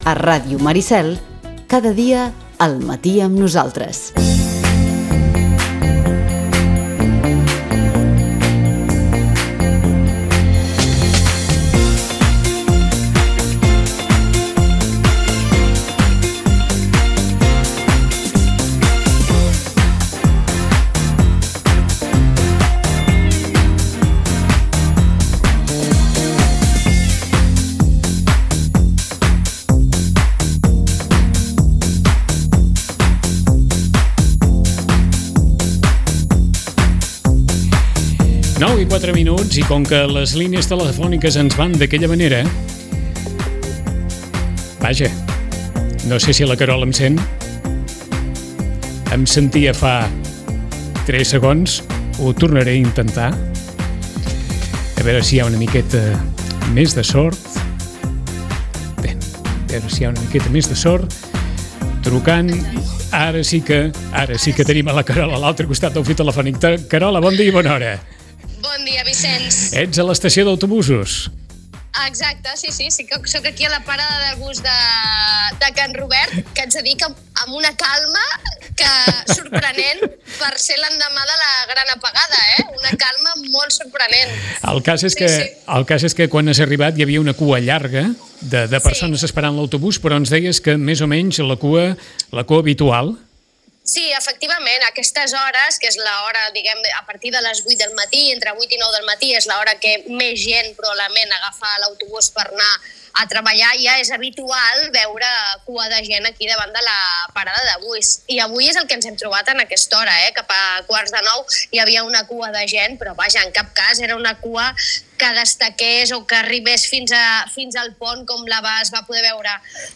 Us oferim un bloc de testimonis que comença a la parada de busos de Can Robert